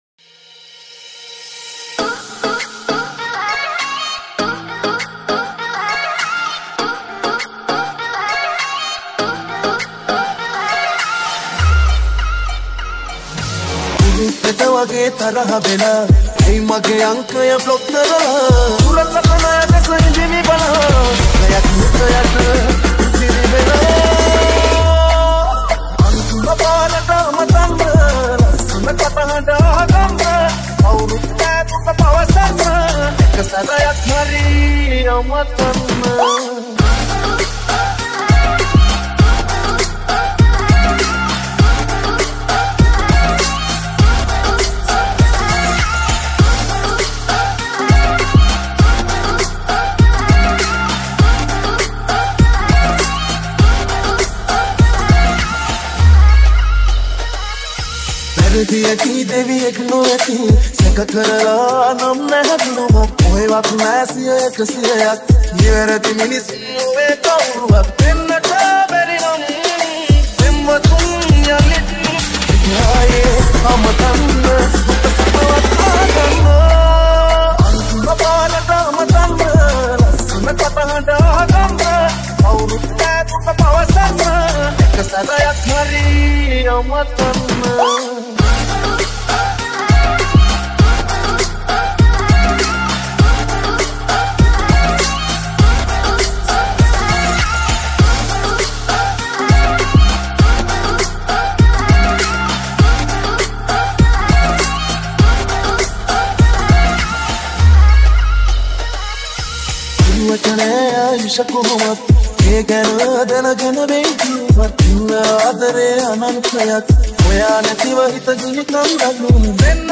Sinhala Dj Remix 2020